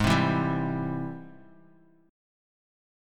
G#+M7 chord